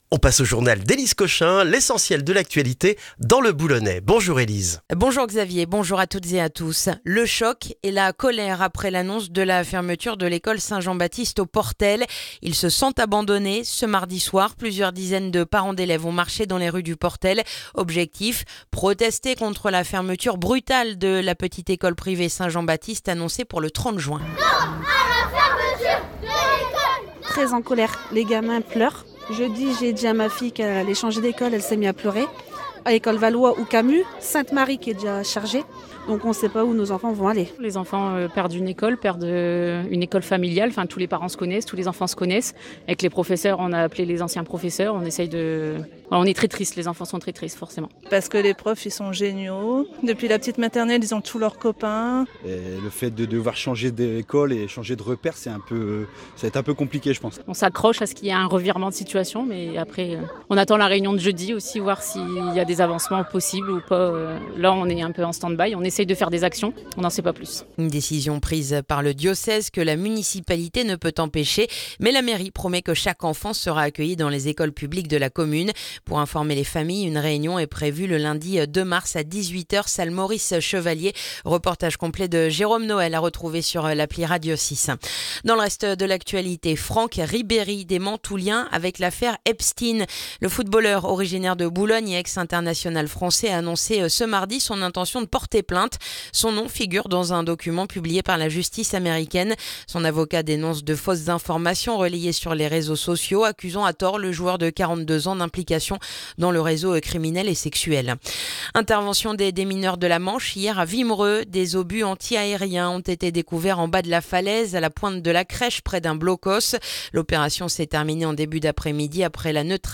Le journal du mercredi 11 février dans le boulonnais